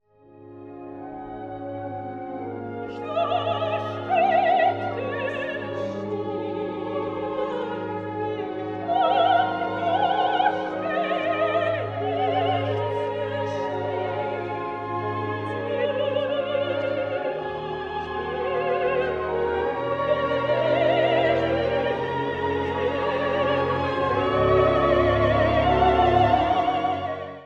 3人の想いを込めた三重唱が歌われます。